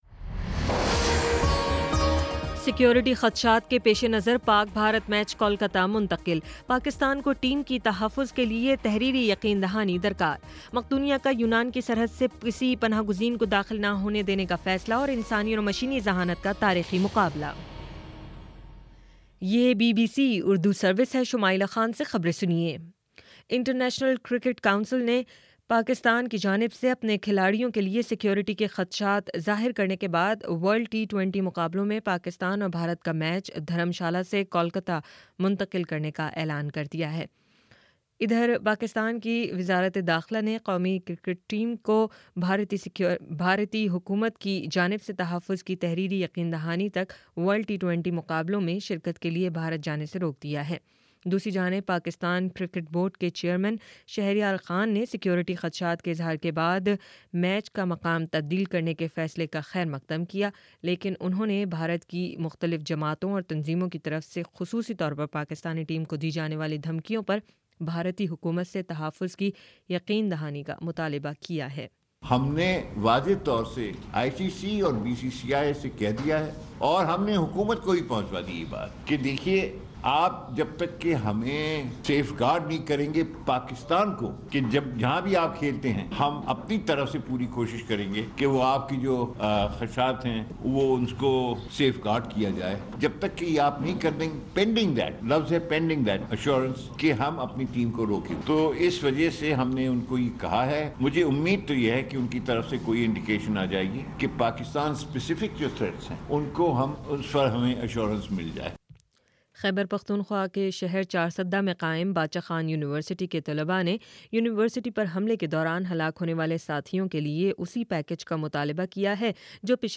مارچ 09 : شام سات بجے کا نیوز بُلیٹن